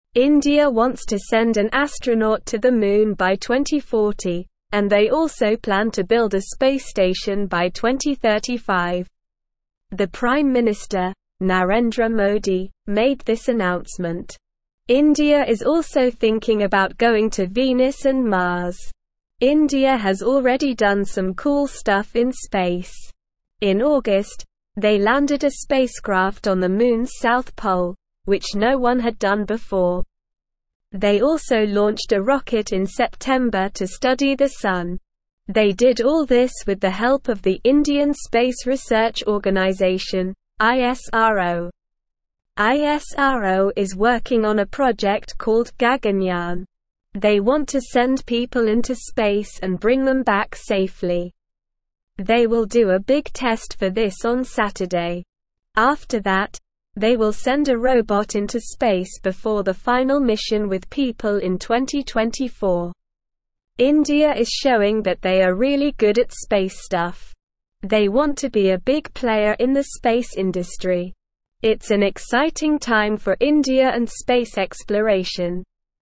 Slow
English-Newsroom-Upper-Intermediate-SLOW-Reading-Indias-Ambitious-Space-Goals-Moon-Mars-and-More.mp3